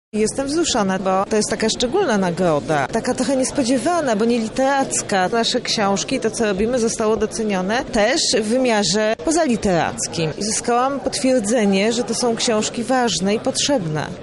gala-wręczenia.mp3